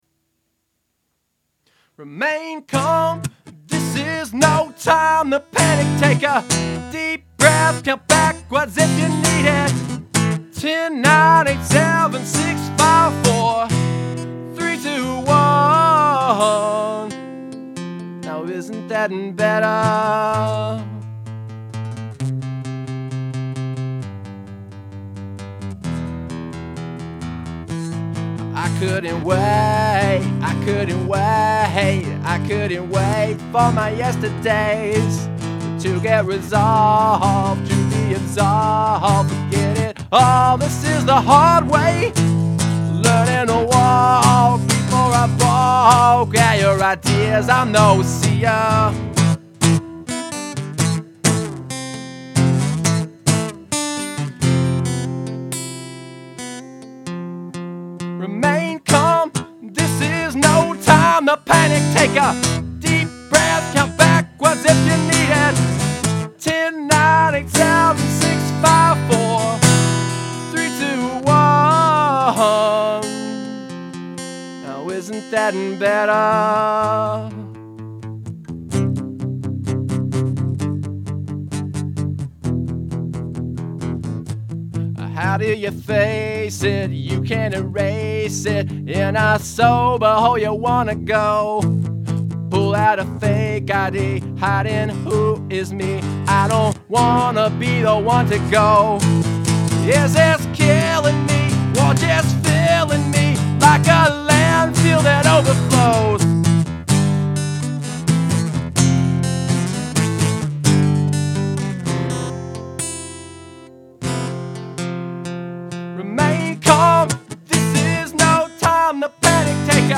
Song must include audible counting